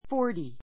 forty 小 A1 fɔ́ː r ti ふォ ーティ 名詞 複 forties fɔ́ː r tiz ふォ ーティ ズ ❶ 40 ; 40人[個]; 40歳 さい ⦣ つづり字に注意: × fo u rty としないこと. ❷ forties で （年齢 ねんれい の） 40代; （世紀の） 40年代 ⦣ forty から forty-nine まで.